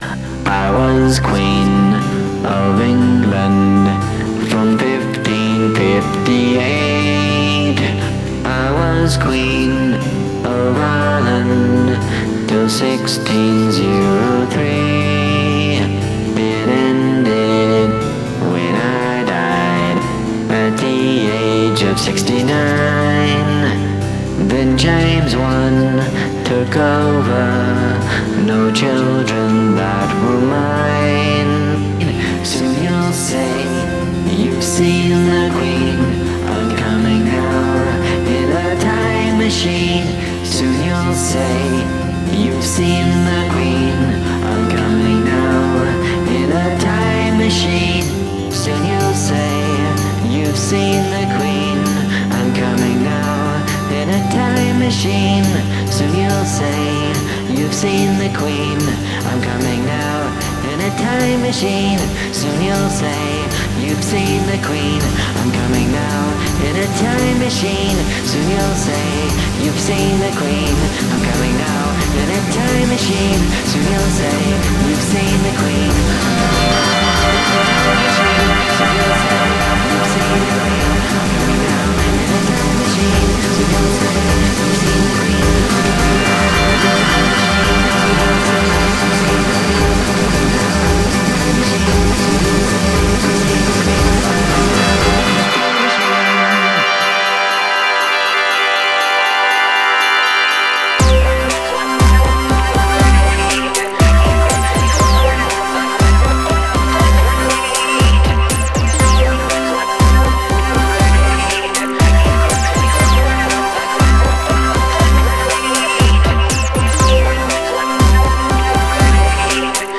Dramatic Change in Tempo